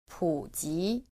• pǔjí